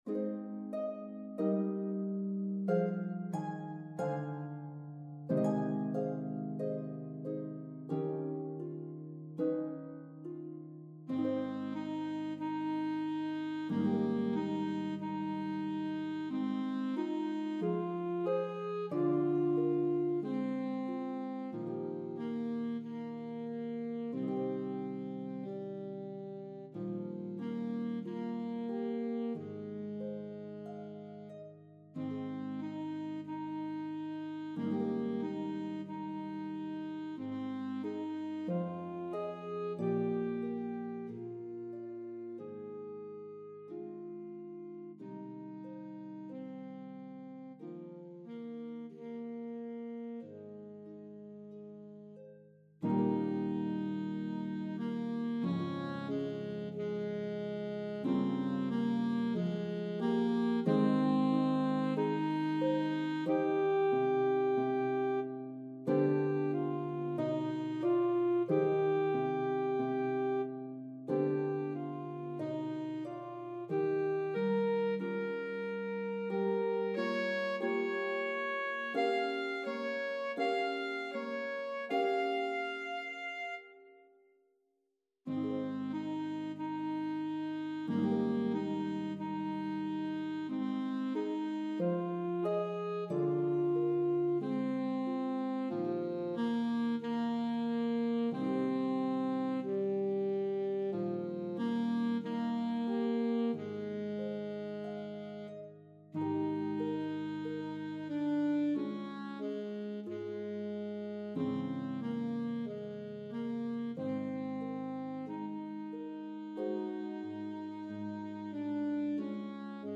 The warmth of this duet will enchant your audience.